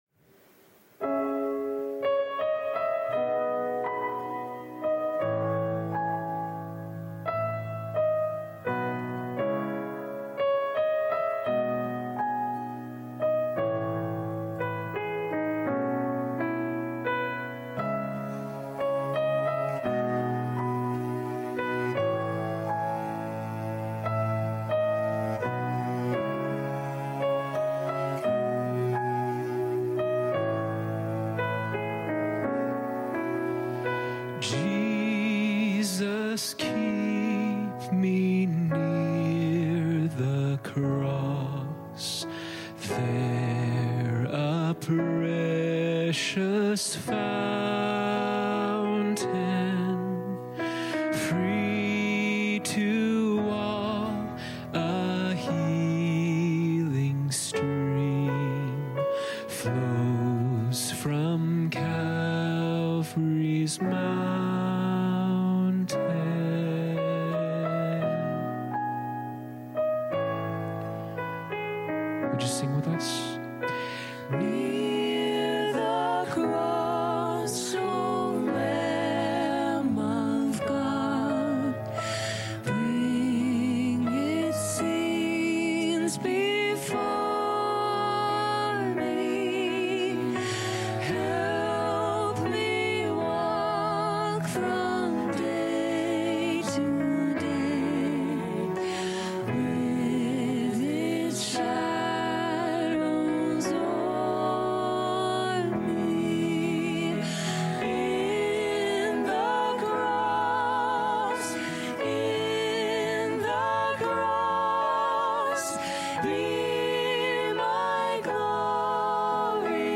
GOOD FRIDAY SERVICES: 5:00 & 7:00 pm (FAC Deerfoot & Online).
English Teaching MP3 This Weekend's Scriptures...